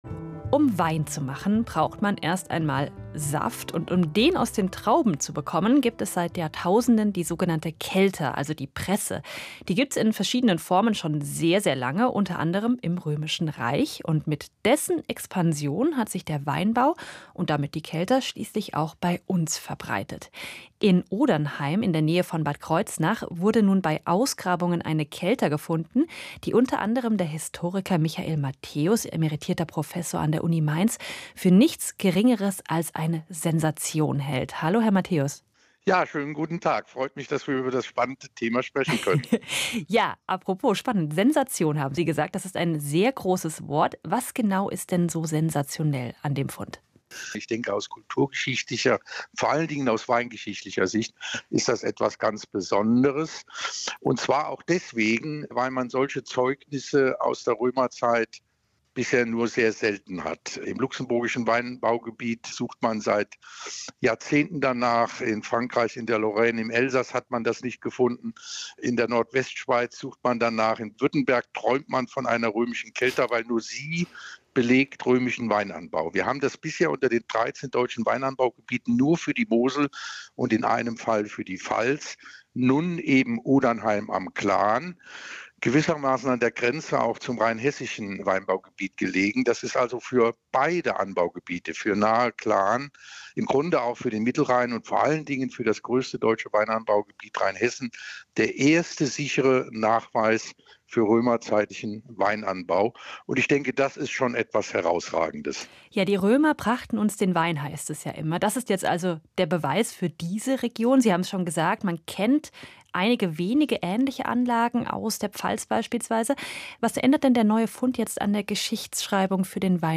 Audio: Römischer Weinkelterfund an Nahe und Glan  | Ausstrahlung: Montag, 31. März 2025 in SWR Kultur am Mittag